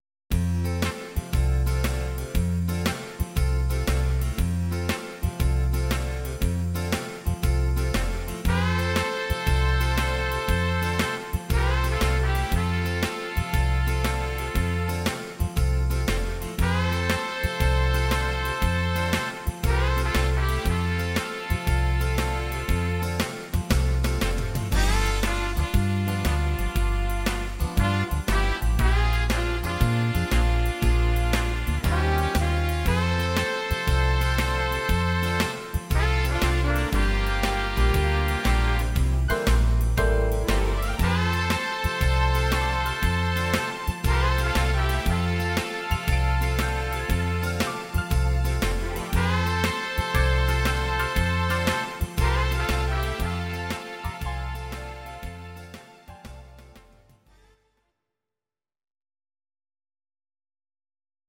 Audio Recordings based on Midi-files
Instrumental